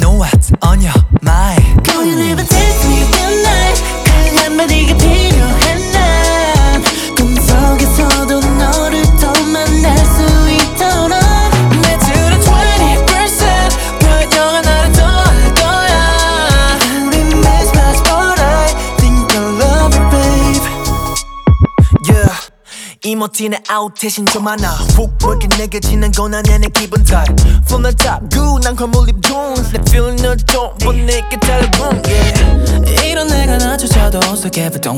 Жанр: Поп музыка
K-Pop